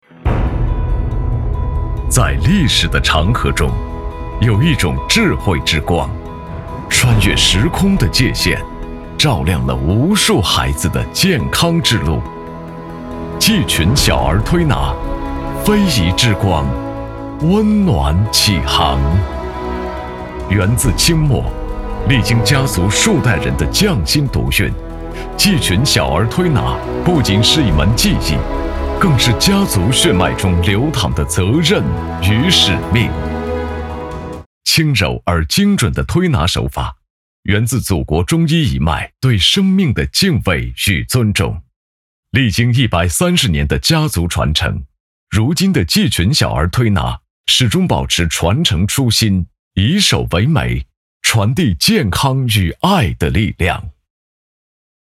男配音-配音样音免费在线试听-第35页-深度配音网
男65浑厚磁性 65